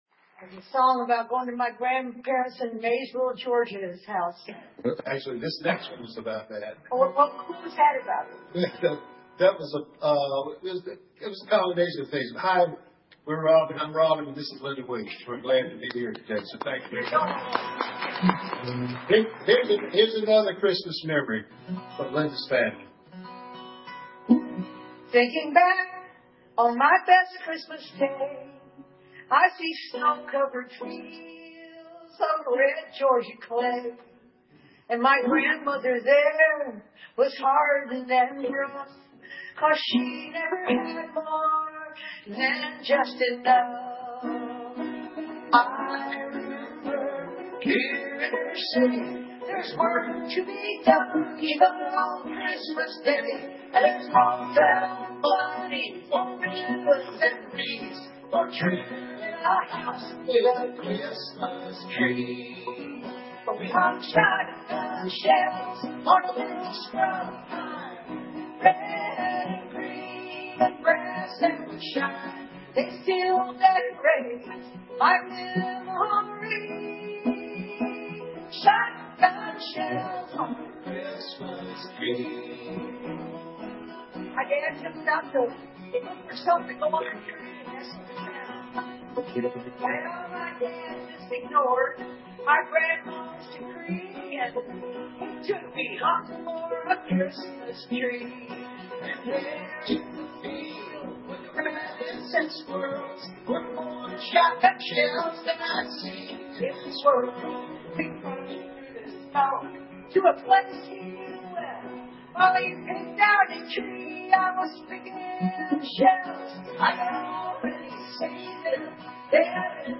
guest musicians